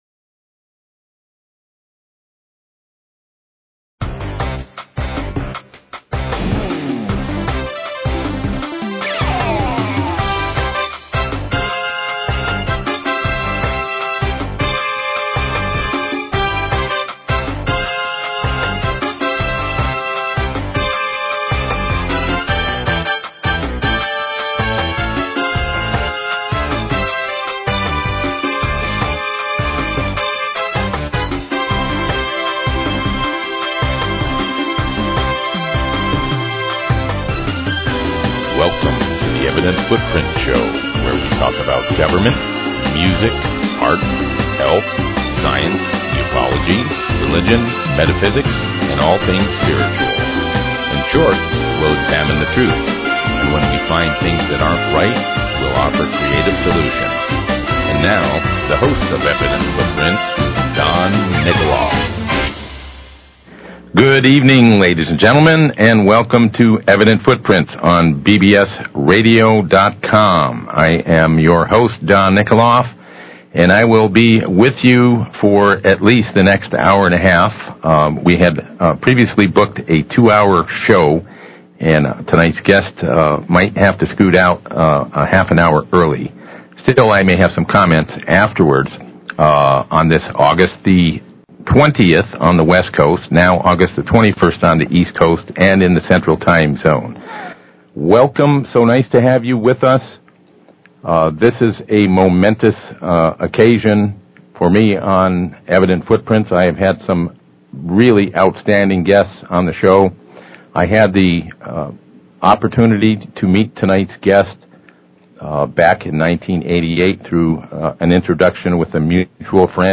Talk Show Episode, Audio Podcast, Evident_Footprints and Courtesy of BBS Radio on , show guests , about , categorized as
Special 2-Hour LIVE Interview w/ VINCENT BUGLIOSI